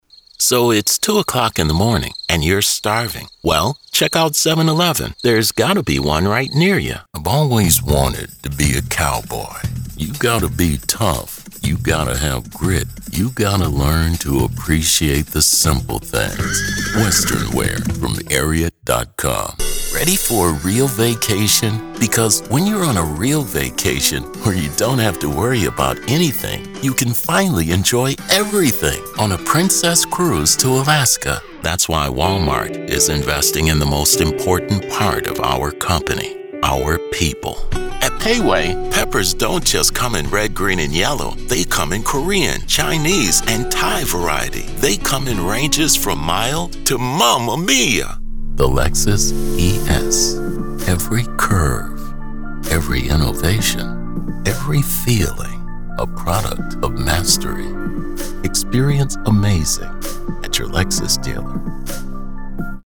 Male
Adult (30-50)
Television Spots
Commercial Demo